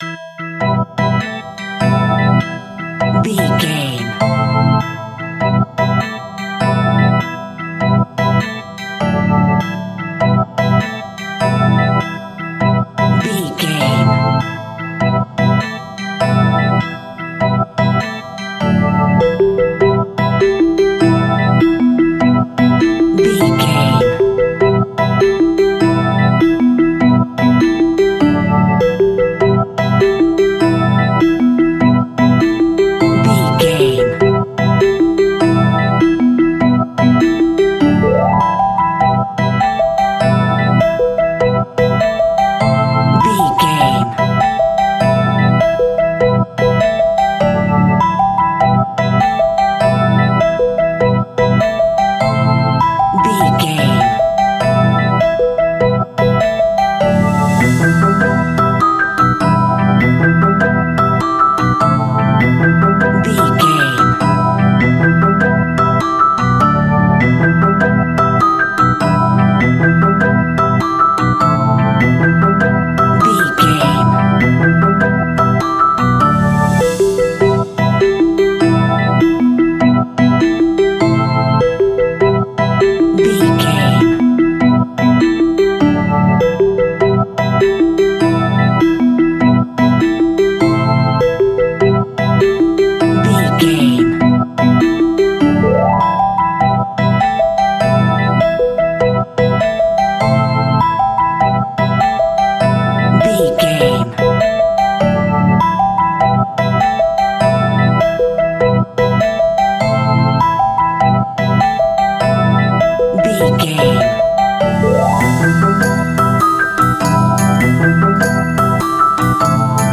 Aeolian/Minor
ominous
eerie
electric organ
synthesiser
drums
strings
piano
spooky
horror music